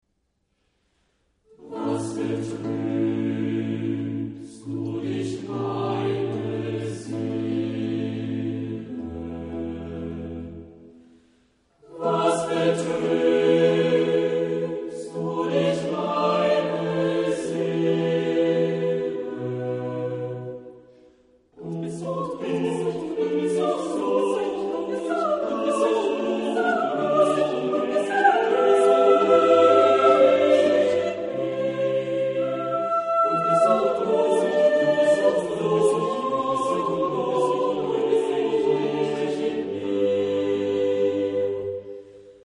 Genre-Style-Forme : Sacré ; Baroque ; Motet
Type de choeur : SSATB  (5 voix mixtes )
Instruments : Orgue (1) ; Clavecin (1)
Tonalité : la mineur